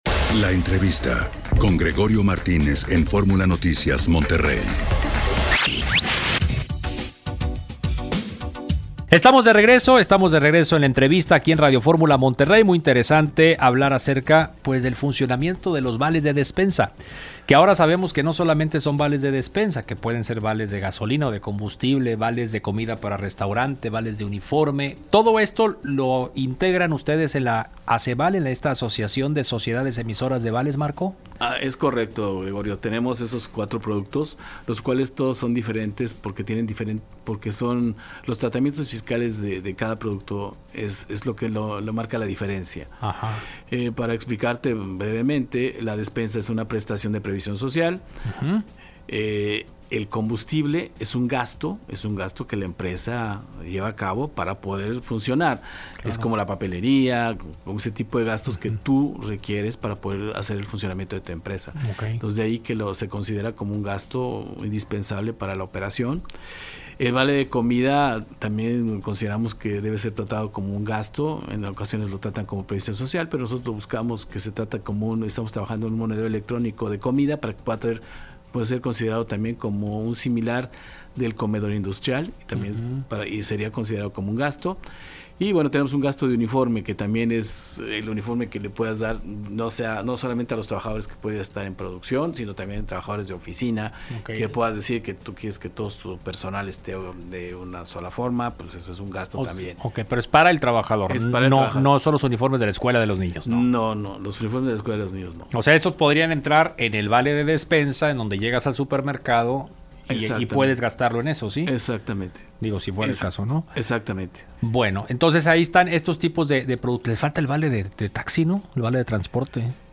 ENTREVISTA EN RADIO FORMULA MONTERREY OCTUBRE 2019 - ASEVAL